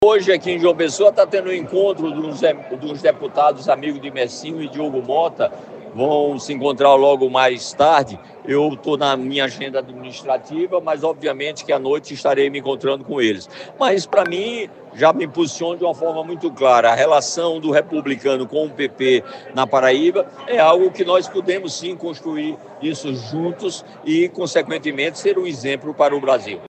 Os comentários do prefeito foram registrados pelo programa Correio Debate, da 98 FM, de João Pessoa, nesta quinta-feira (09/01).